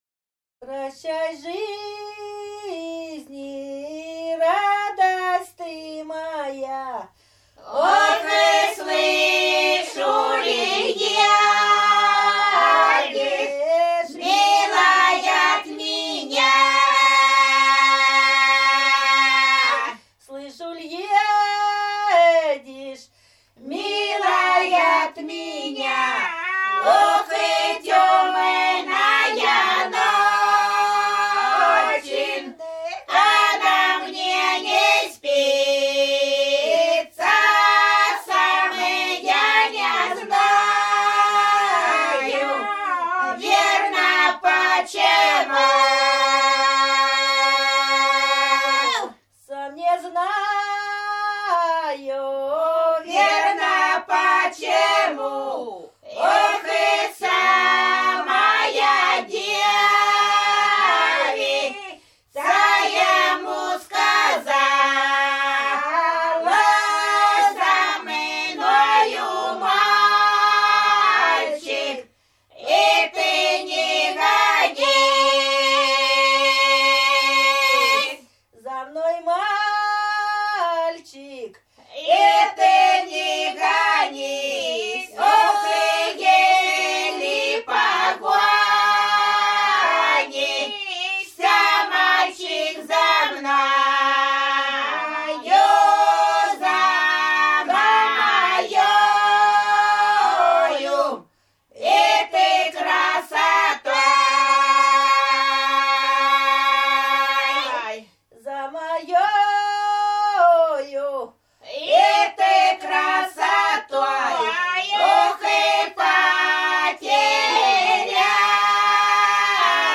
Рязань Кутуково «Прощай жизнь», лирическая.